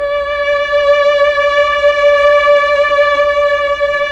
STR_TrnVlnD_5.wav